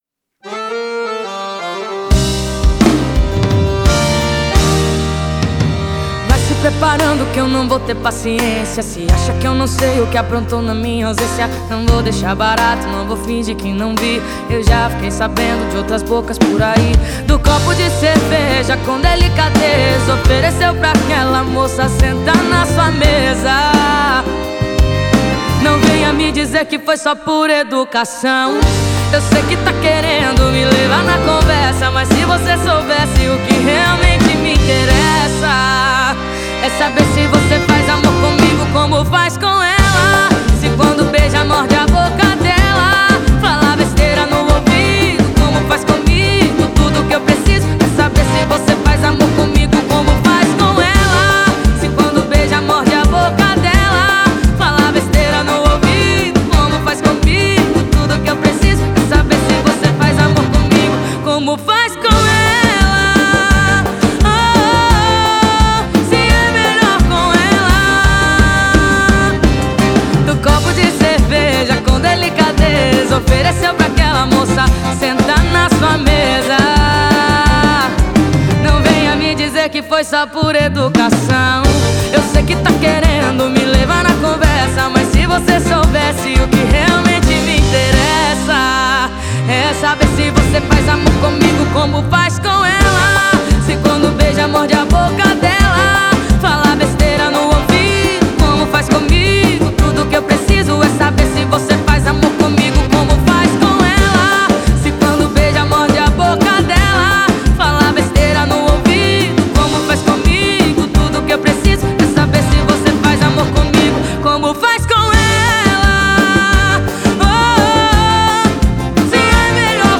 2025-01-01 22:08:03 Gênero: Sertanejo Views